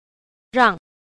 2. 讓 – ràng – nhượng (để, khiến)